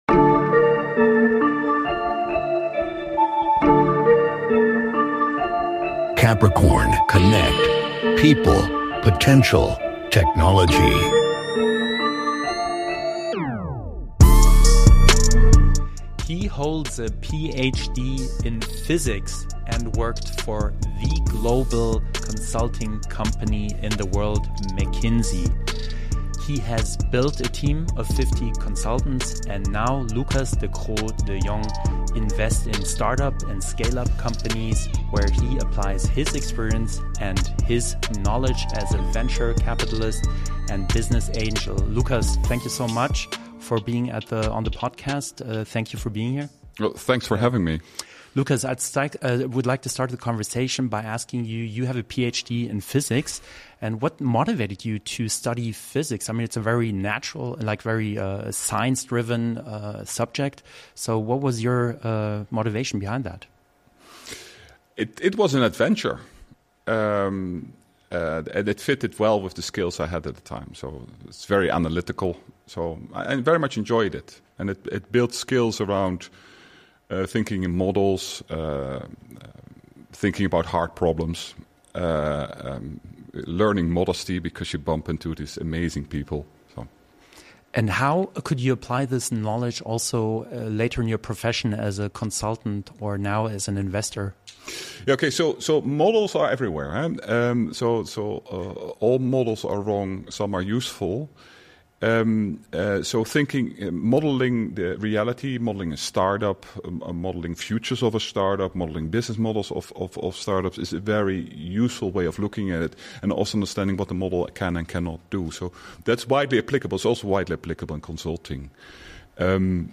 #76 - Interview